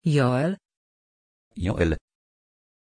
Pronunciation of Joel
pronunciation-joel-pl.mp3